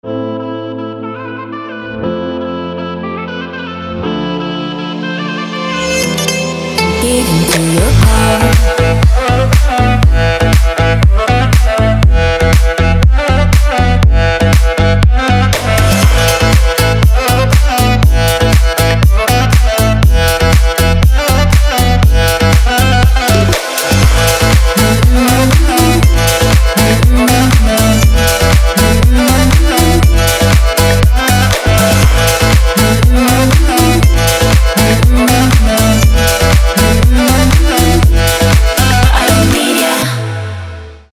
• Качество: 320, Stereo
deep house
восточные мотивы
dance
Стиль: dance pop, deep house